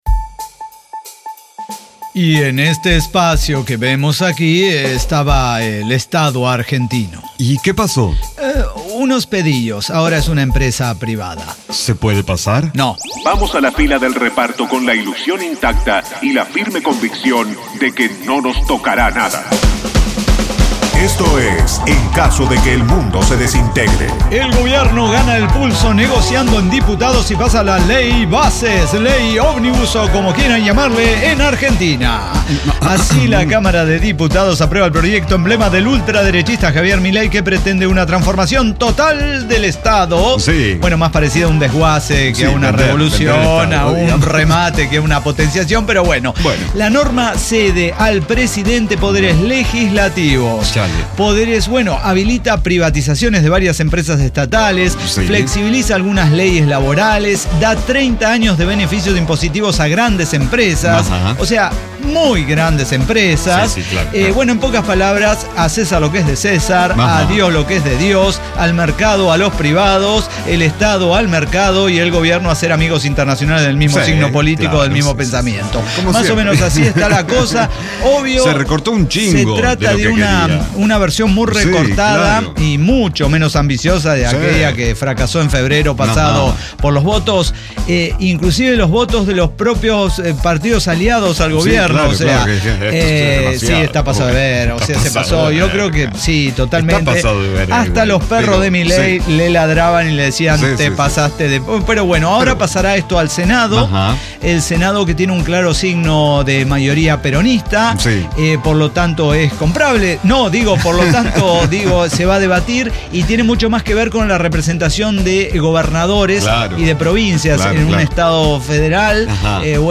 ECDQEMSD podcast El Cyber Talk Show – episodio 5773 Día Del Trabajo